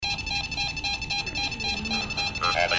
The following clips were obtained from Burn Brae Mansion.
DR1 – downstairs dining room
This is a shortened file with just the moan.